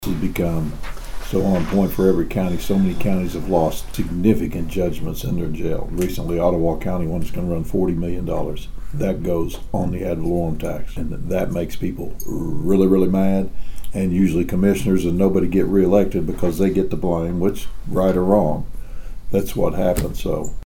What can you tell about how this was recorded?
The Washington County Commissioners talked jails and new employees at their weekly Monday meeting in downtown Bartlesville.